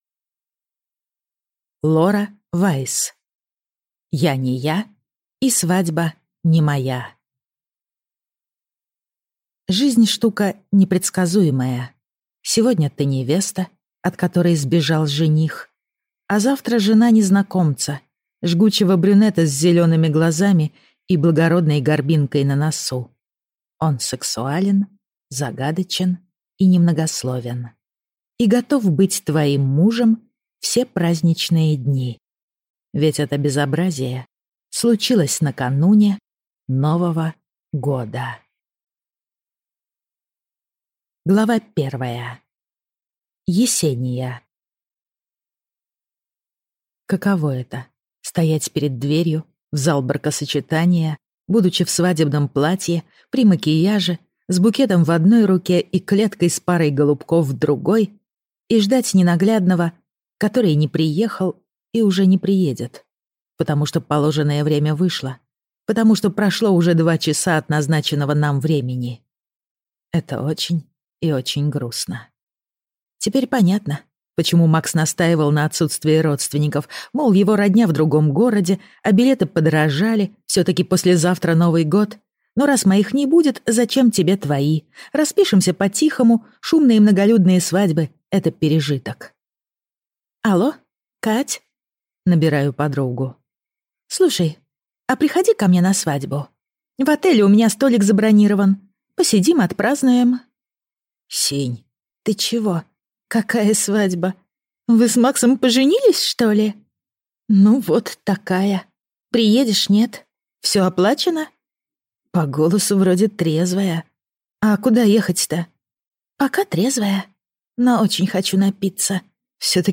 Аудиокнига Я не я и свадьба не моя | Библиотека аудиокниг